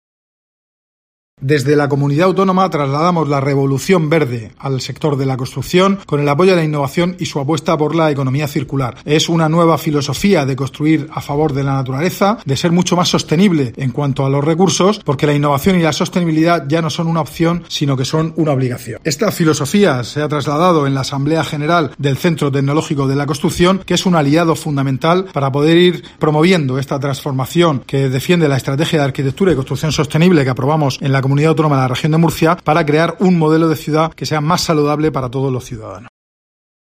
Celebración del 20 aniversario del Centro Tecnológico de la Construcción
José Ramón Díez de Revenga, consejero de Fomento en funciones